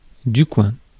Ääntäminen
Ääntäminen France: IPA: [kwɛ̃] Haettu sana löytyi näillä lähdekielillä: ranska Käännöksiä ei löytynyt valitulle kohdekielelle.